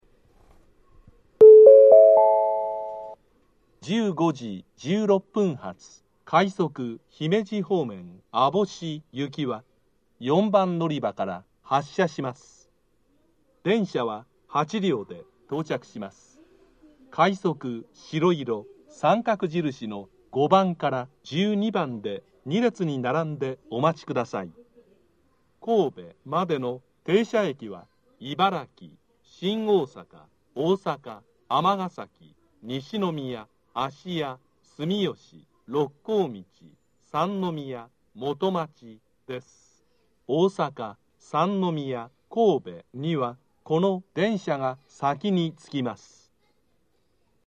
（男性）
到着予告放送 15：16発 快速 網干行き 8両編成の自動放送です。
雑音が多いですがご容赦ください。